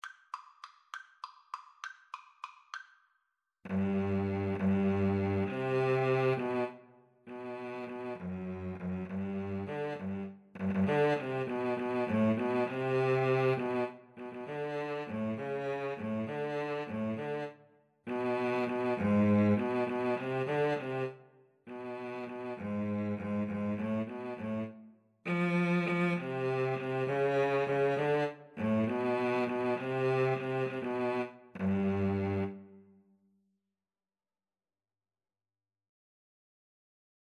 3/8 (View more 3/8 Music)
Cello Duet  (View more Easy Cello Duet Music)
Classical (View more Classical Cello Duet Music)